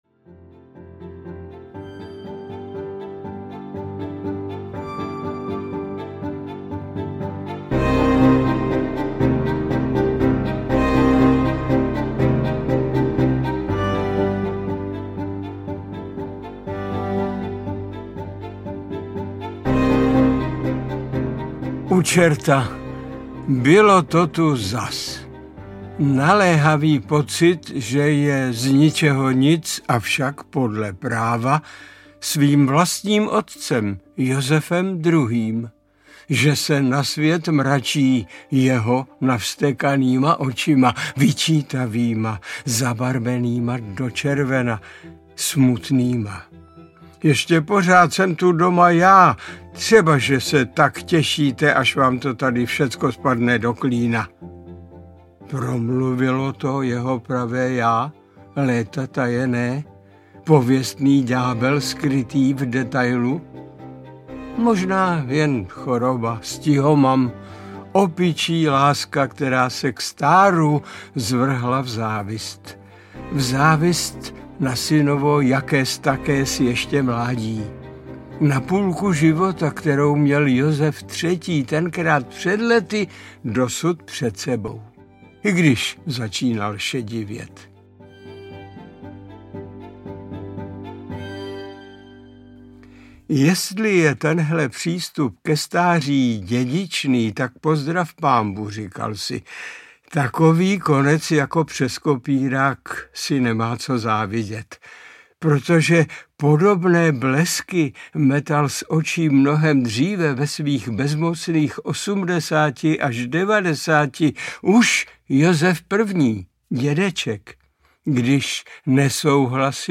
Josefové audiokniha
Ukázka z knihy
• InterpretMiloň Čepelka